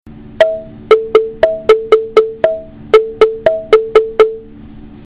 スリット・ドラム(Lokole)：音色(1)
共鳴孔の真ん中にスリットが入っていて、その部分を叩くと意外と大きな音がするのは、その唇の大きさと共鳴胴の体積との比率がうまく考えられているからでしょう。
• その唇は、下の拡大写真でも判りにくいのですが、両方の長さと分厚さが変えてあり、音程がはっきりと違っています。
二つの音程の違いをうまく使って、楽しいリズムが作れます。
その唇は片持ち梁になっていて、その厚さを胴から唇の先にかけて微妙に薄くなるように削ってあり、これが「トウゥーーィーン」という絶妙な揺れをもたらして、「わぁーーー、もろアフリカやんけ。」という感興をもたらします。
lokole1.mp3